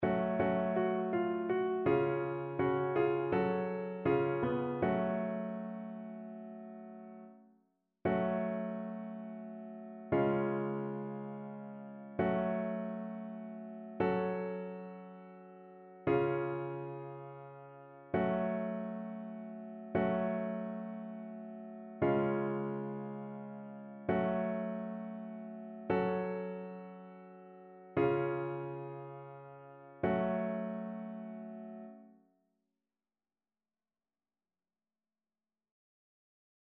annee-c-temps-ordinaire-17e-dimanche-psaume-137-satb.mp3